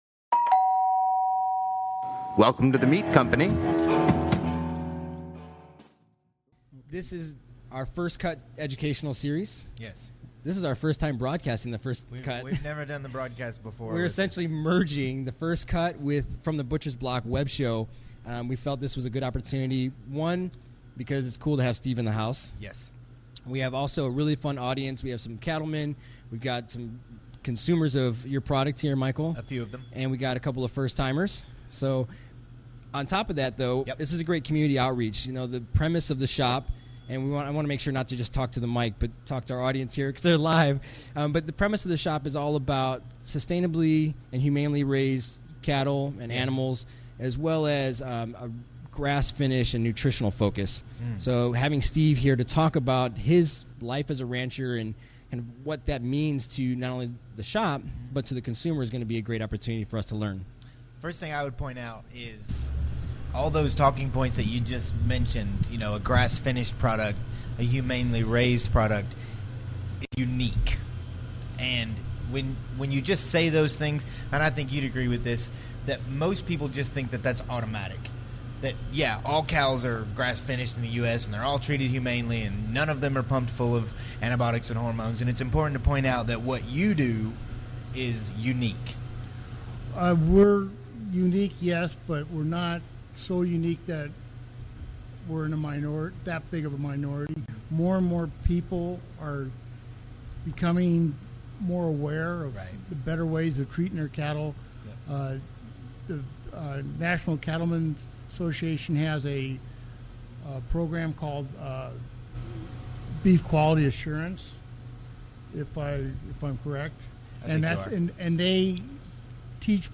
Enjoy the candid discussion.